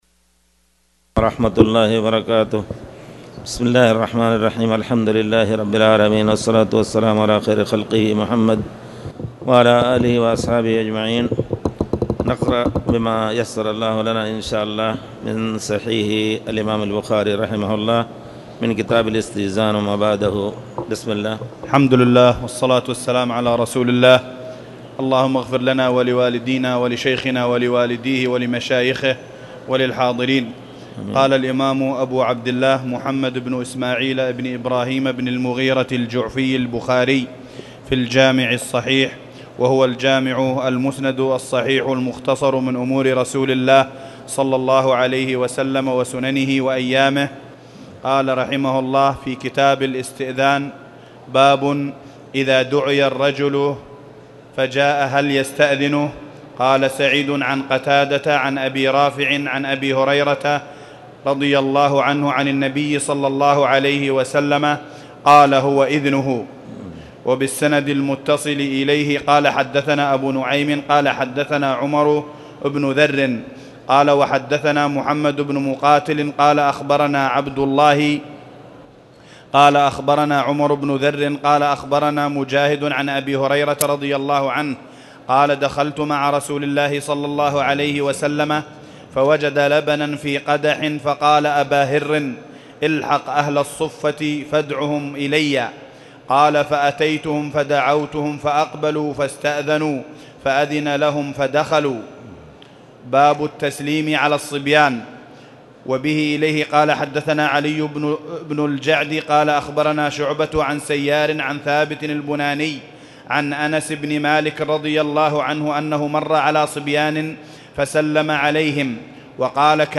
تاريخ النشر ١٦ ربيع الثاني ١٤٣٨ هـ المكان: المسجد الحرام الشيخ